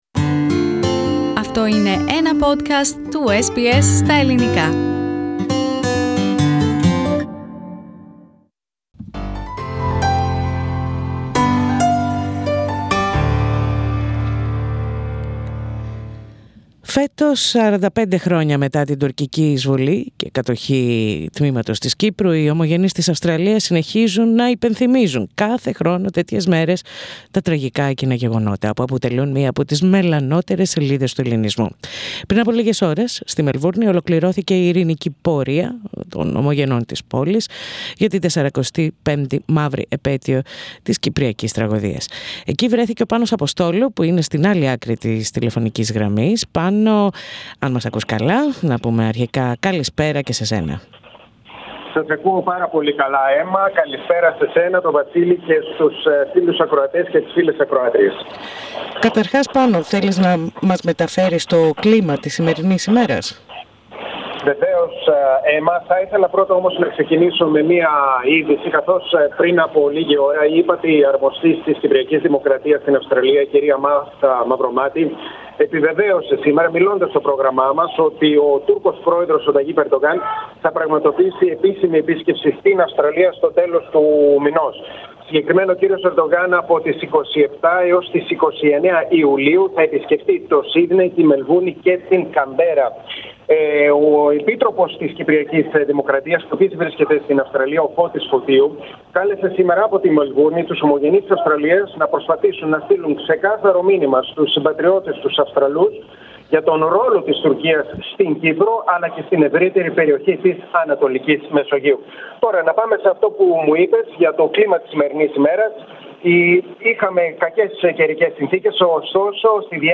Hundreds of Greeks and Cypriots of Melbourne took part in the annual peaceful march in Melborne CBD. SBS Greek was there and reports.